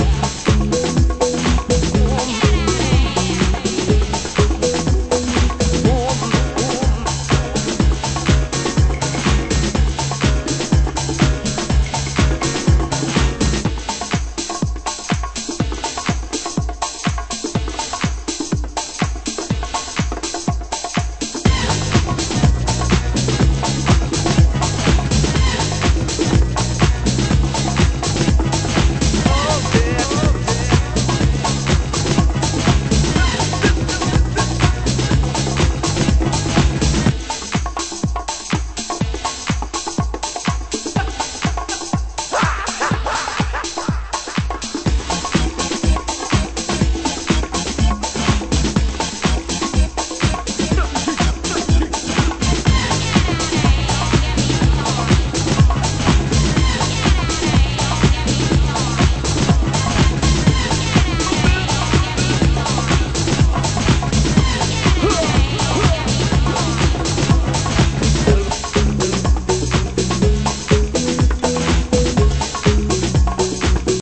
盤質：盤面綺麗ですが少しチリノイズ有